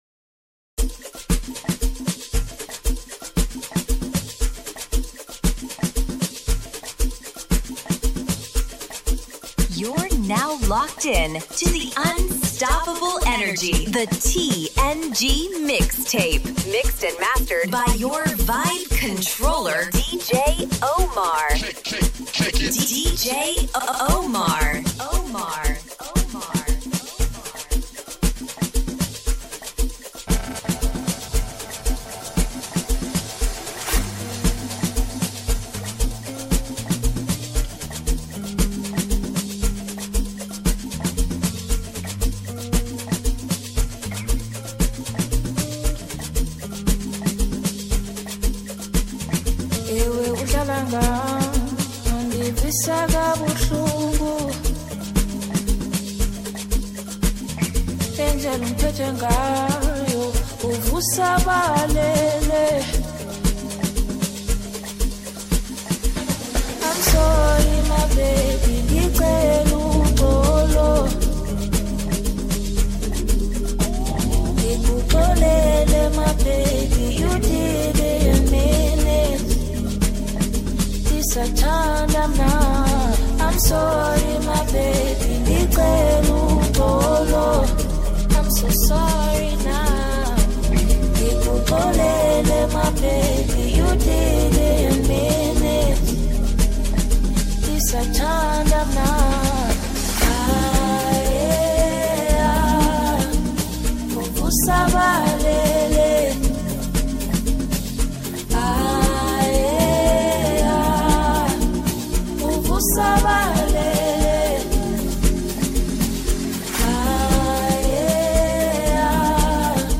” blending Afrobeats and Amapiano.